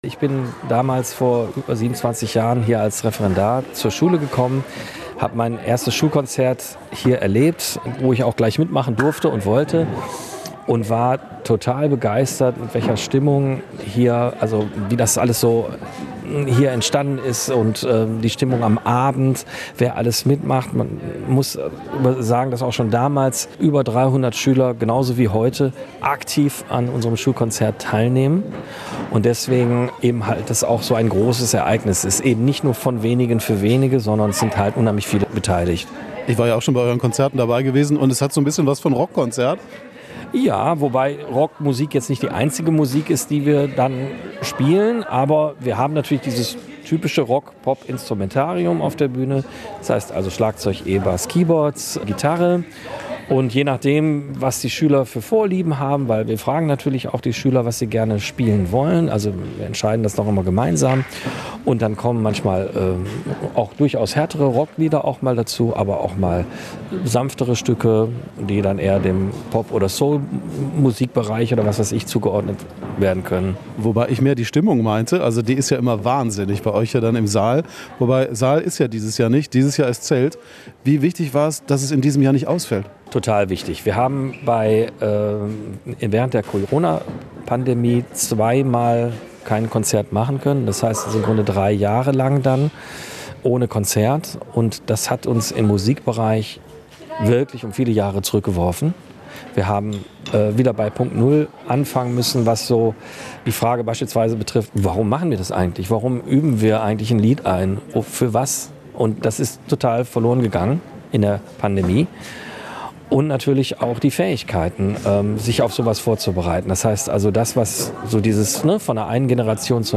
Wir waren bei den Proben.